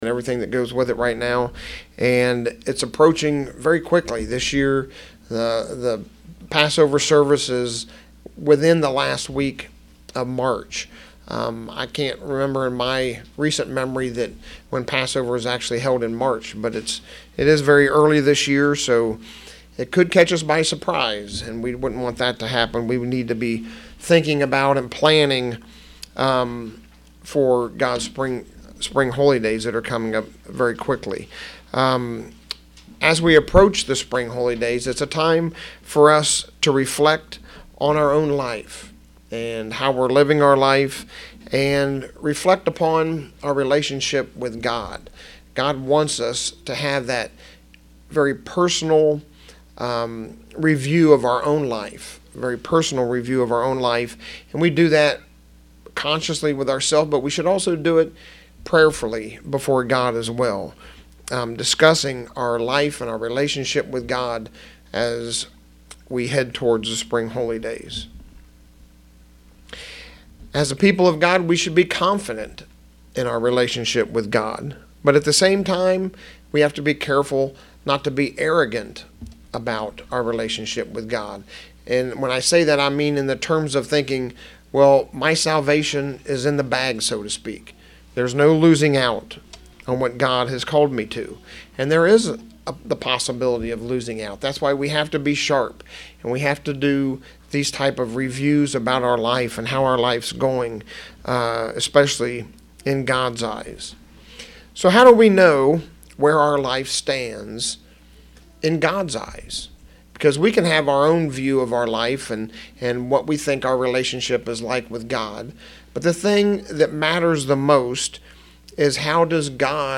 Given in York, PA